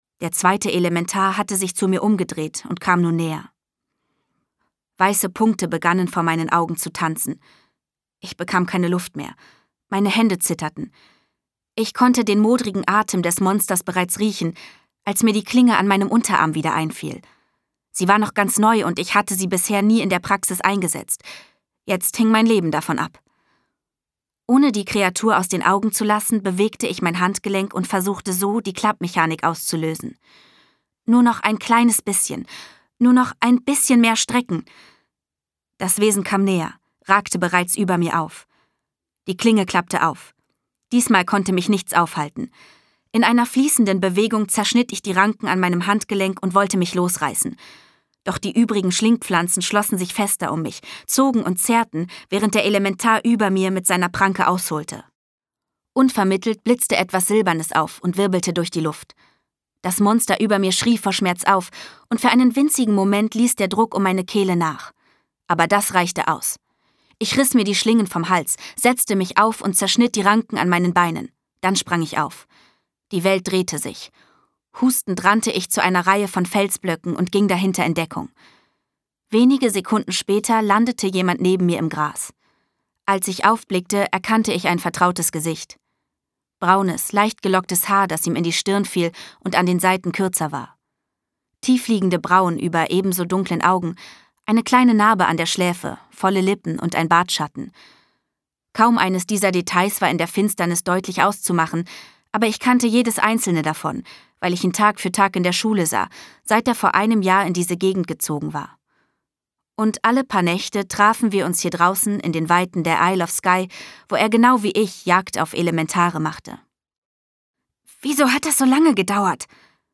Hörbuch: Sturmtochter.